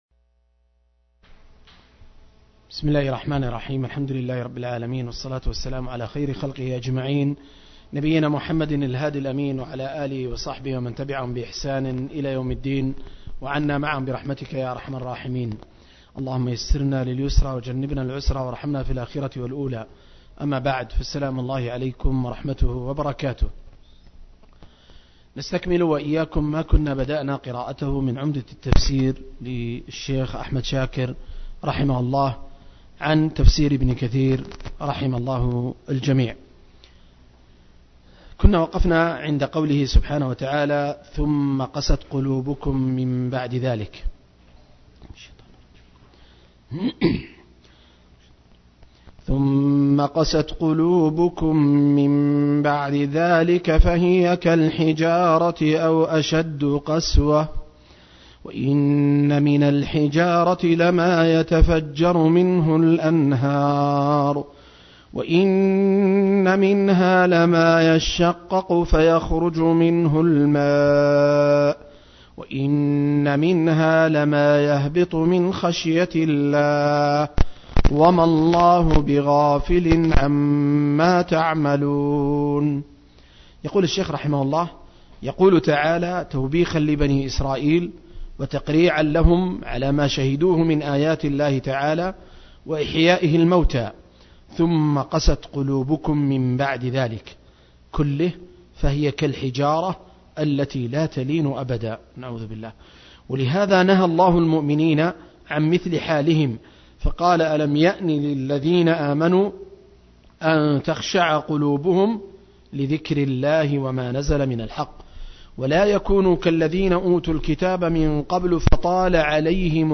المجلس التاسع عشر: تفسير سورة البقرة (الآيات 74-79)